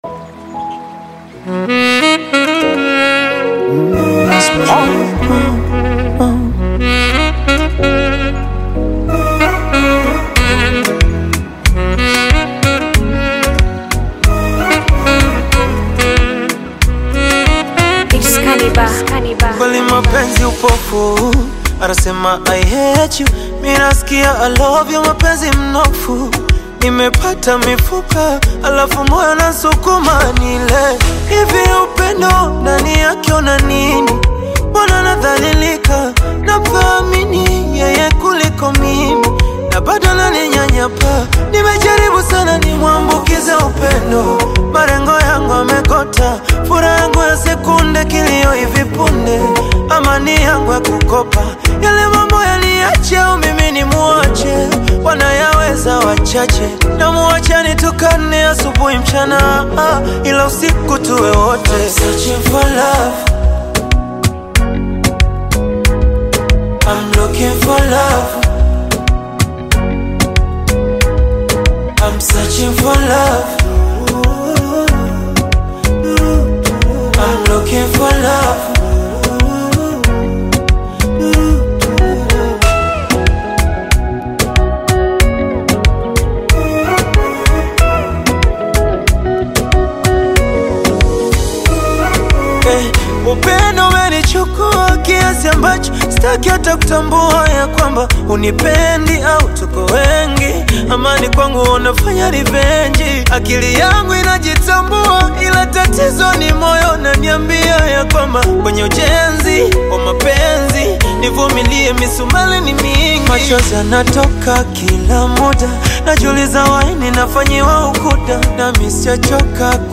soul-stirring ballad
#R&B